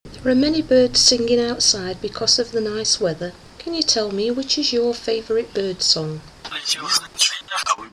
Sony B300 and reversed Russian background